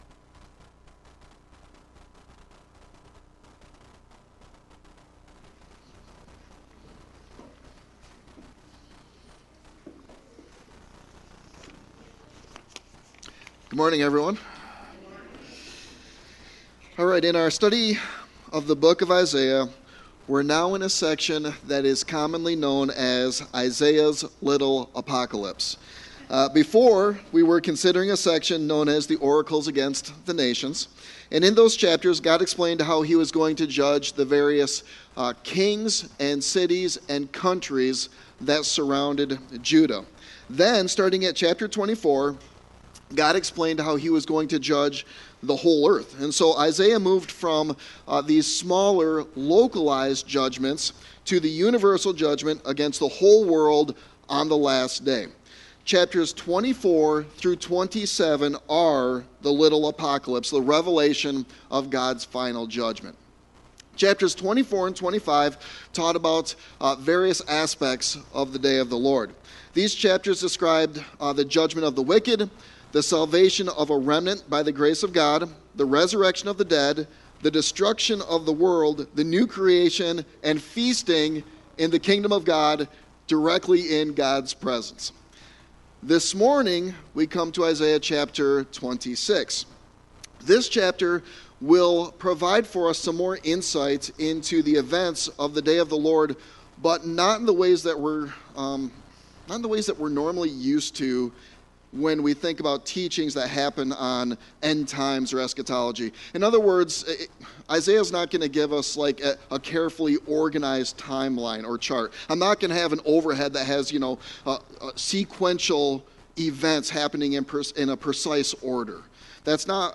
Sermon Text